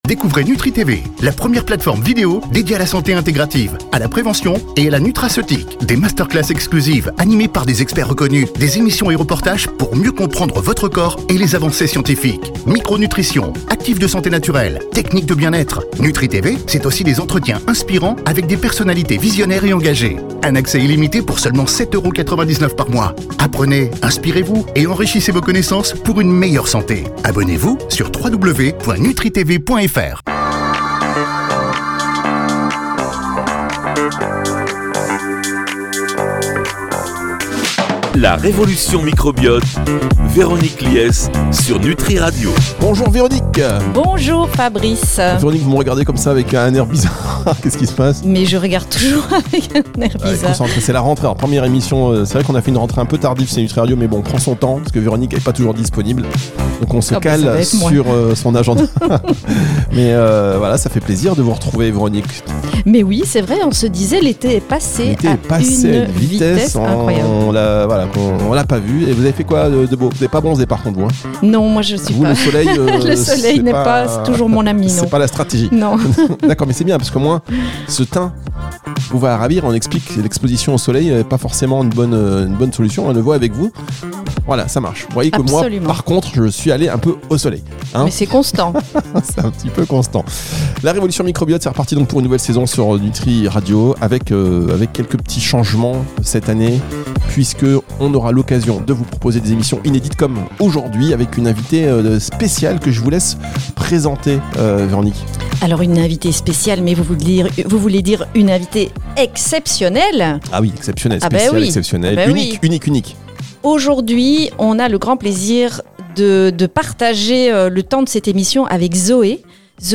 Un échange passionnant, bienveillant et sans tabou, pour comprendre, choisir en conscience et manger mieux au quotidien.